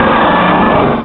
pokeemerald / sound / direct_sound_samples / cries / swampert.aif